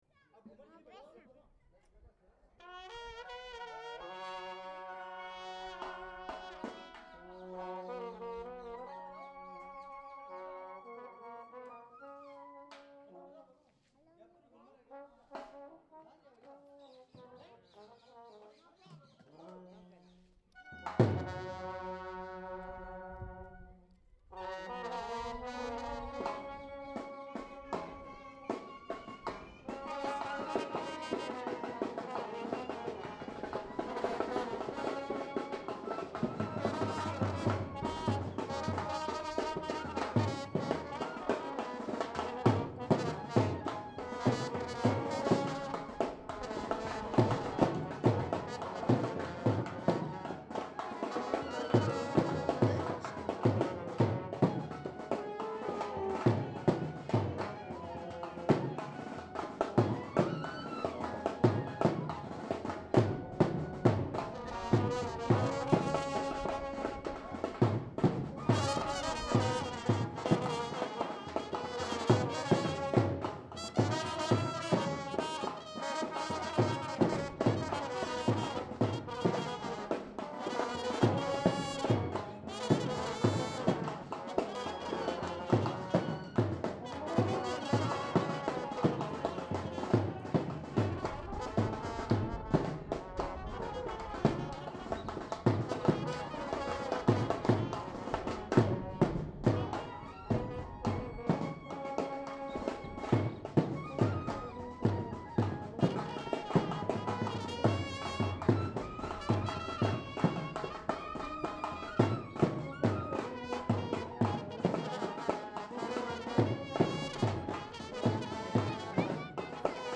fanfare | Prete moi tes yeux